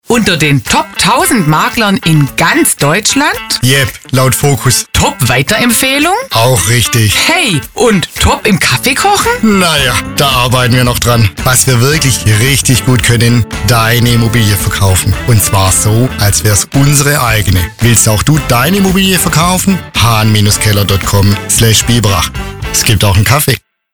Unser neuer Radiospot